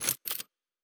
pgs/Assets/Audio/Sci-Fi Sounds/Weapons/Weapon 06 Reload 3.wav at master
Weapon 06 Reload 3.wav